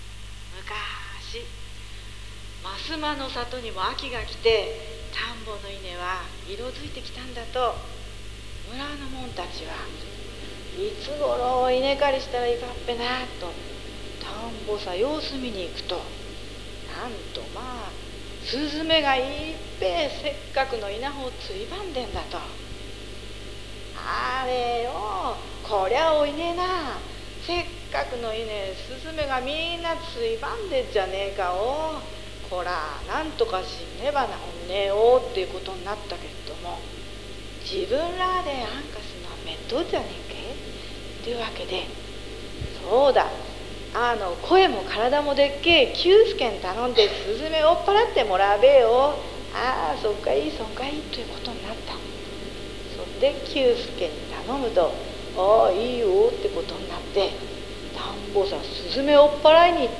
茨城の民話（茨城弁）